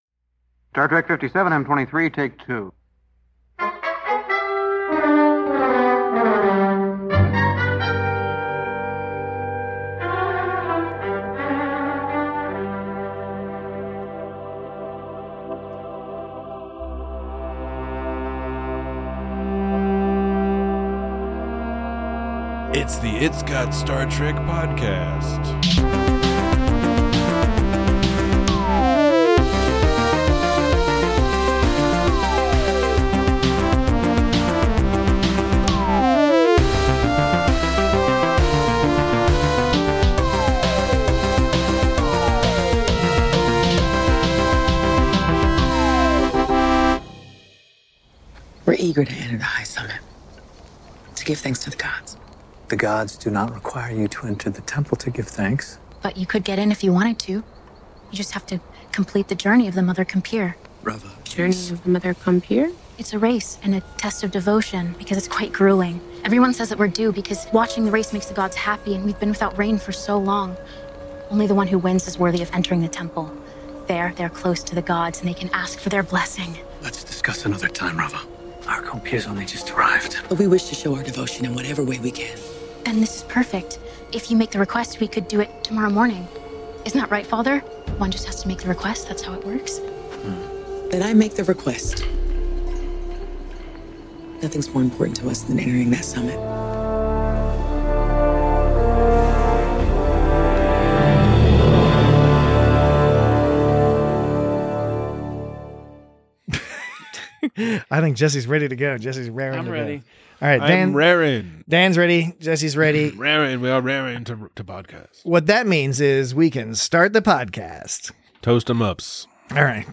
In their rush to find the next clue, Tilly and Burnham almost kill a priest's child. Join your parched hosts as they discuss excellent direction, comforting throw-back plots, and the value of accessible character motivations.